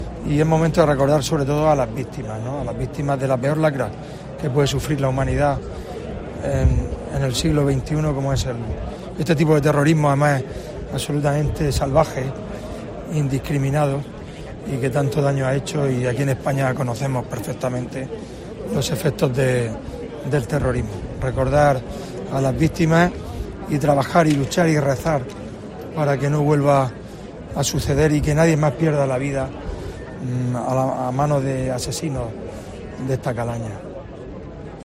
Minuto de silencio en Lorca en el vigésimo aniversario de los atentados de Madrid